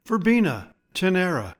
Pronounciation:
Ver-BEE-na te-NER-a